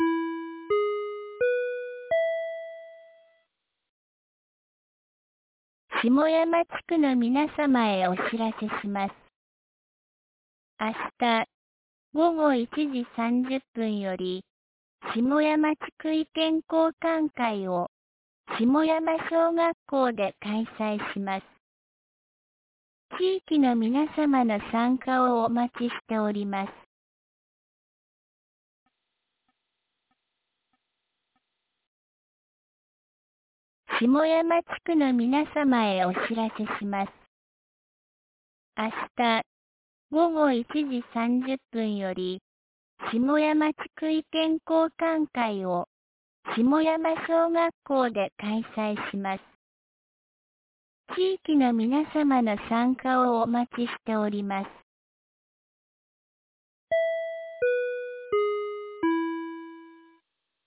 2024年10月28日 18時01分に、安芸市より下山へ放送がありました。